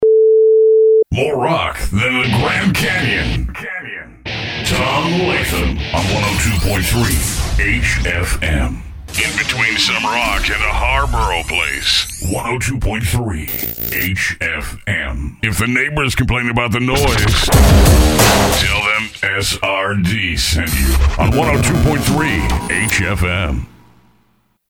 1029HFM Imaging Reel short
Category: Radio   Right: Personal
Tags: Voiceover Radio Voice voice artist Imaging internet imaging